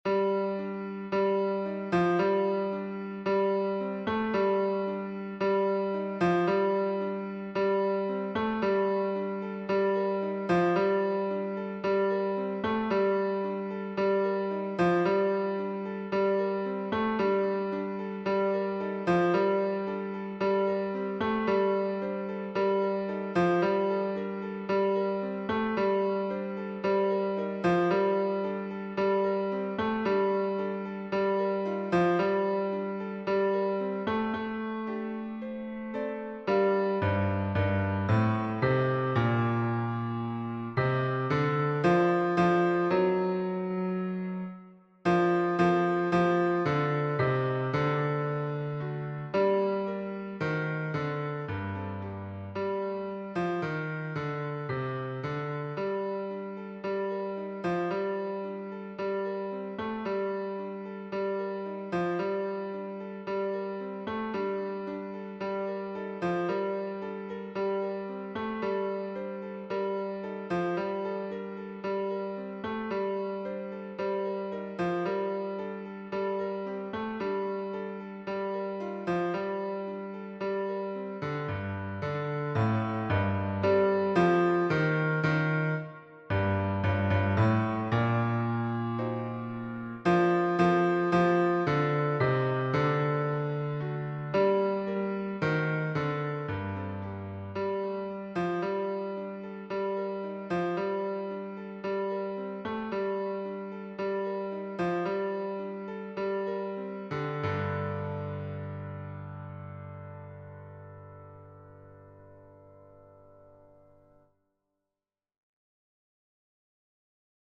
Basse Piano